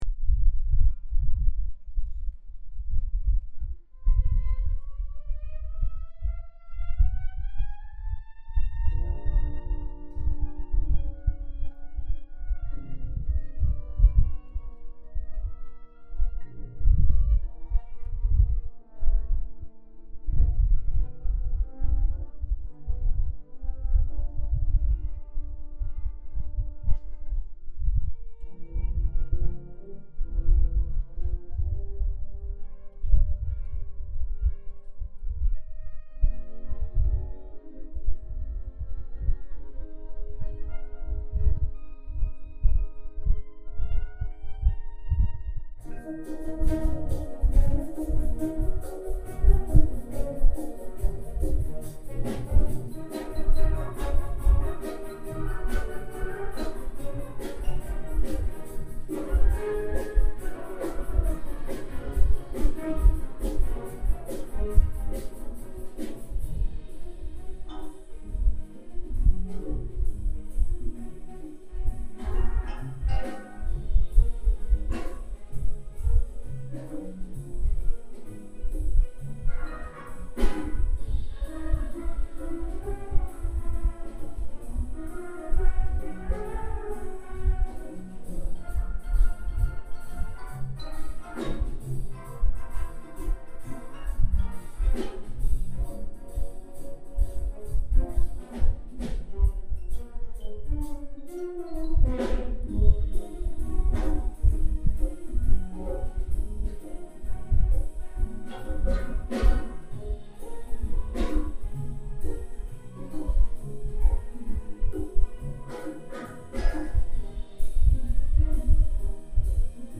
Rhapsody in Blue - Concert Band